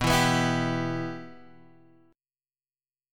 Bsus4#5 chord